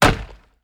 Chopping wood 4.wav